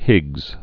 (hĭgz)